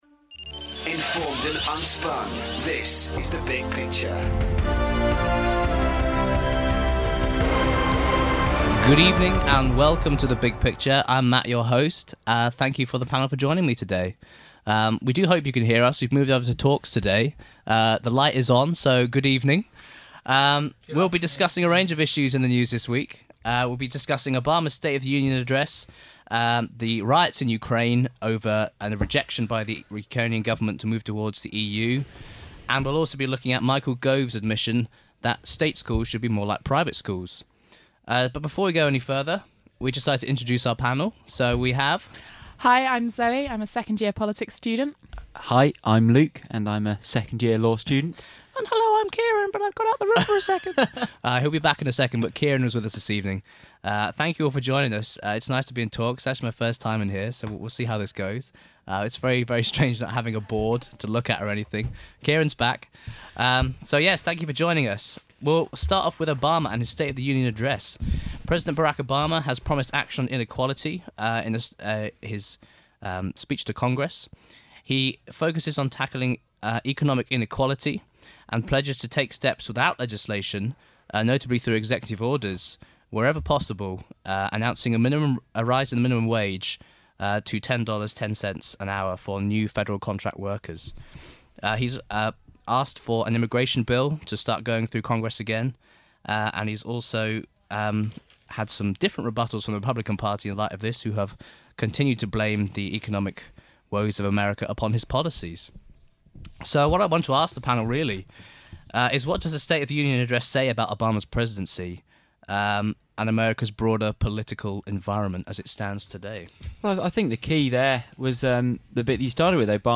In this show the panel discuss Obama's State of the Union address, Michael Gove's comments on the gulf between State and private schools, and the London Underground strike action. 43:55 minutes (40.21 MB) big picture education london michael gove Obama private schools state of the union state schools strike tube 8 comments Download audio file